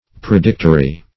Predictory \Pre*dict"o*ry\, a.